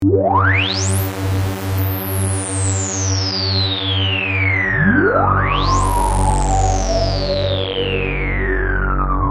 Free MP3 vintage Sequential circuits Pro-600 loops & sound effects 2
Sequencial Circuits - Prophet 600 41